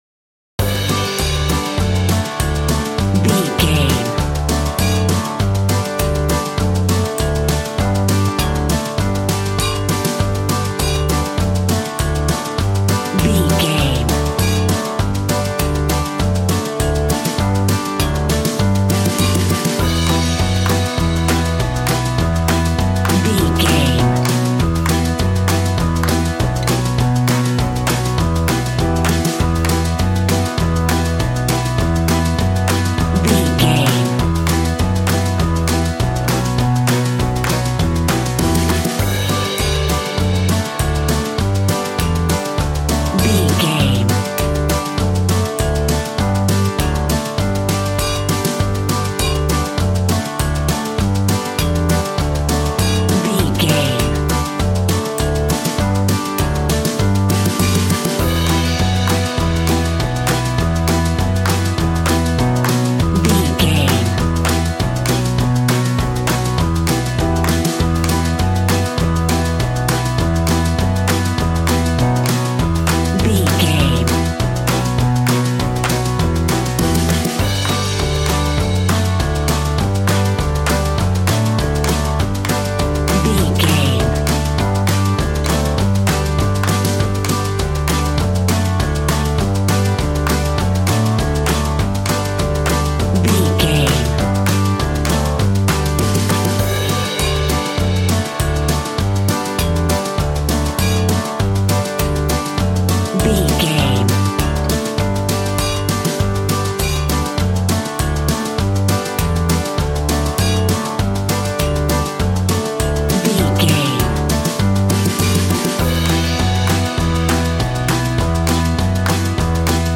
Upbeat, uptempo and exciting!
Ionian/Major
cheerful/happy
bouncy
electric piano
electric guitar
drum machine